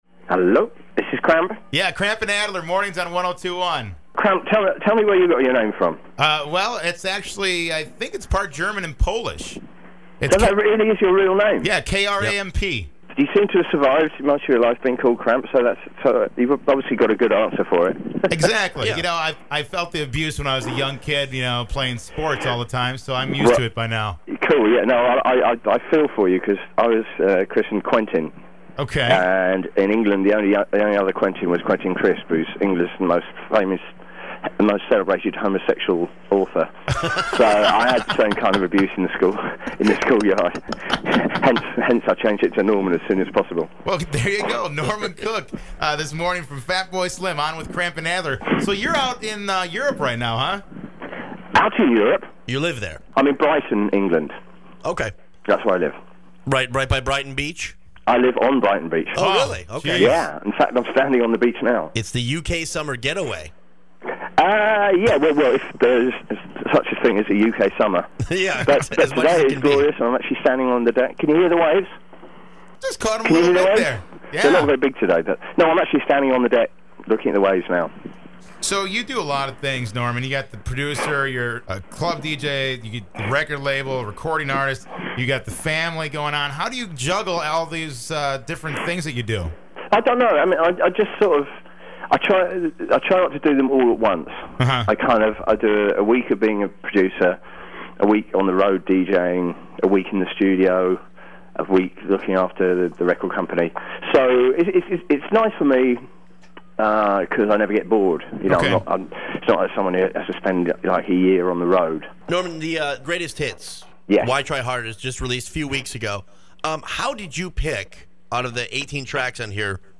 fatboy-slim-interview.mp3